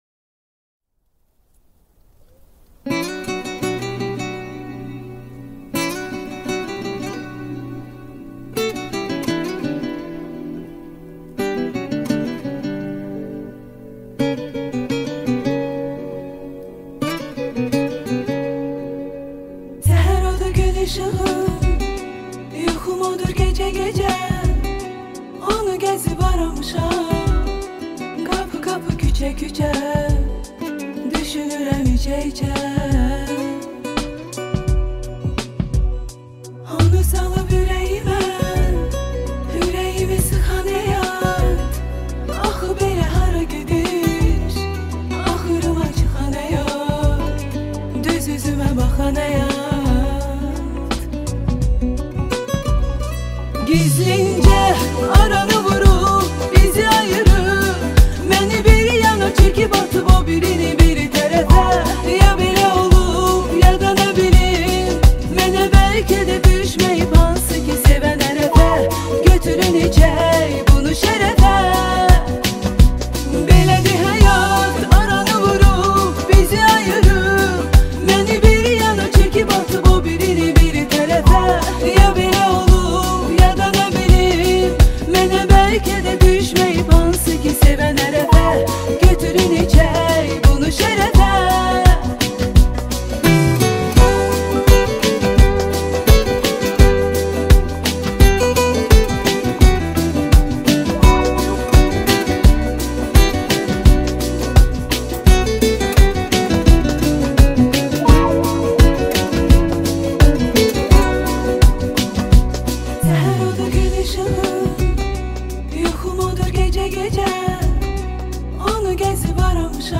نسخه ترکی با صدای زن